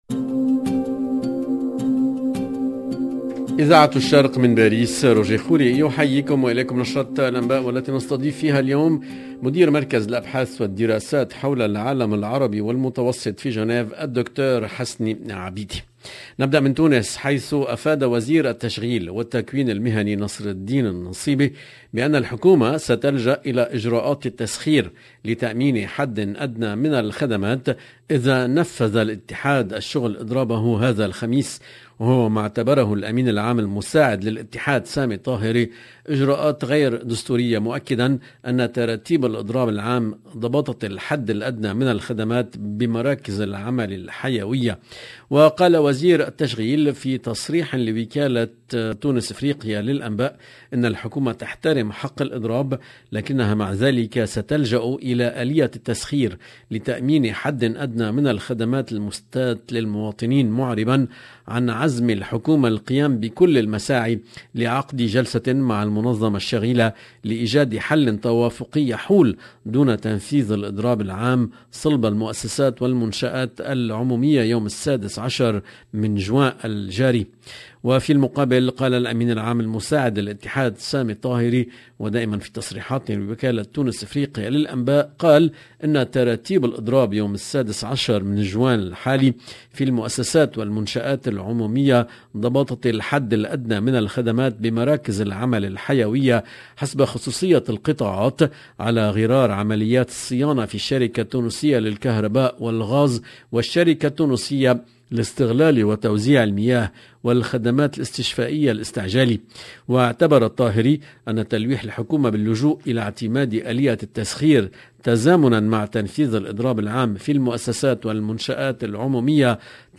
LE JOURNAL EN LANGUE ARABE DU SOIR DU 15/06/22